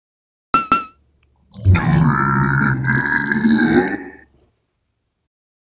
fire-3.wav